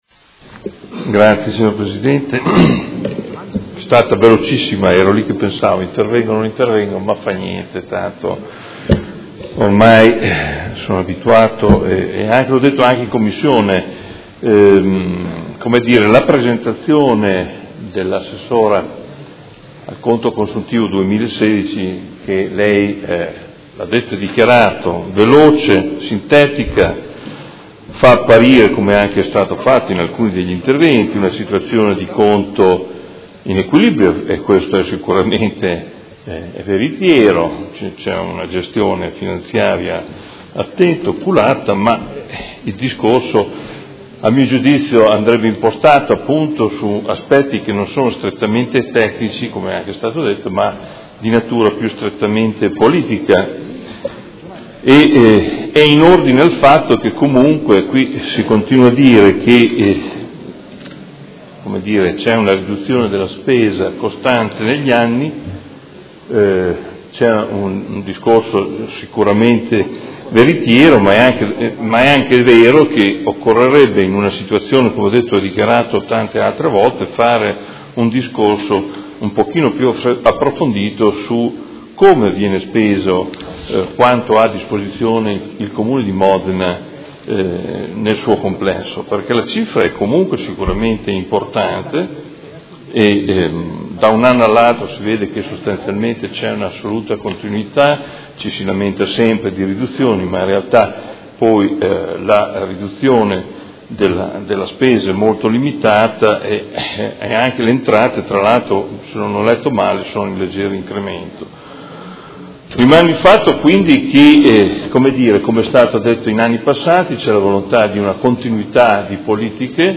Seduta del 27/04/2017 Dichiarazione di voto.